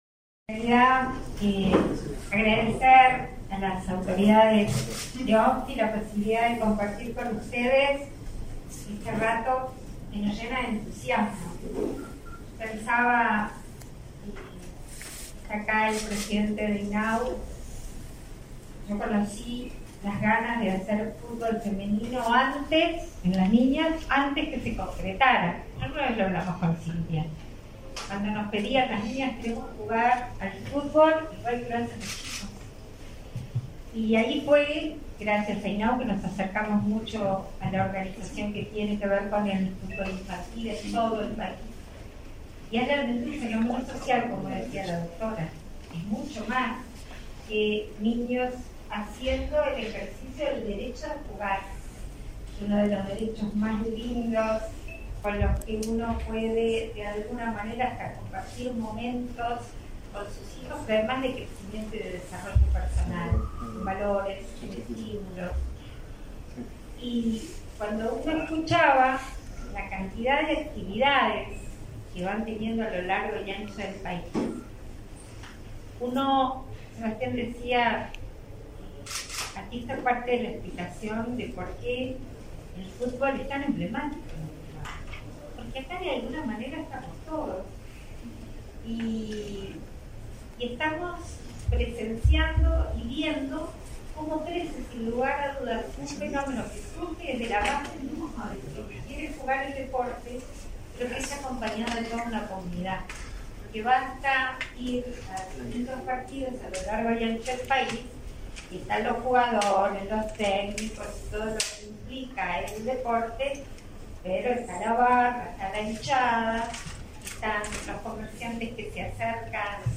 Conferencia de prensa por el lanzamiento de la temporada 2022 de la Organización Nacional de Fútbol Infantil
Conferencia de prensa por el lanzamiento de la temporada 2022 de la Organización Nacional de Fútbol Infantil 15/03/2022 Compartir Facebook X Copiar enlace WhatsApp LinkedIn Con la presencia de la vicepresidenta de la República, Beatriz Argimón, y del secretario nacional del Deporte, Sebastián Bauzá, se realizó, este 15 de marzo, el lanzamiento de la temporada 2022 de la Organización Nacional de Fútbol Infantil.